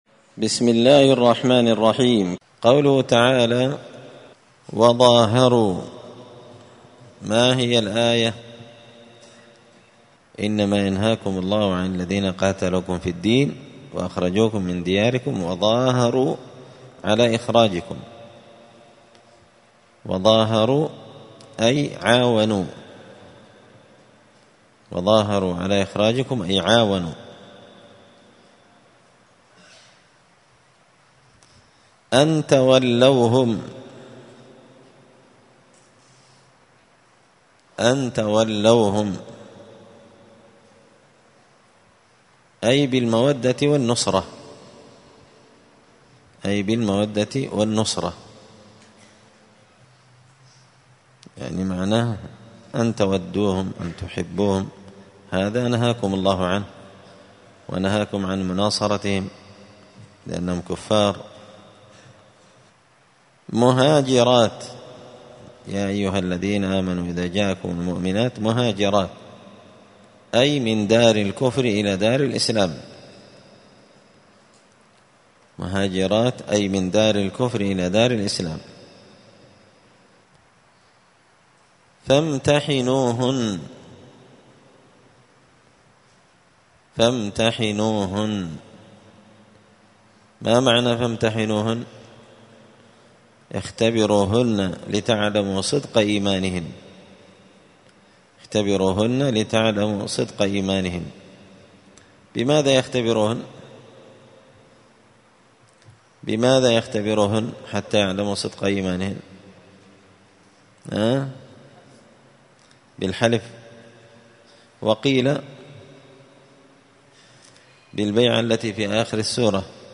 *(جزء المجادلة سورة الممتحنة الدرس 136)*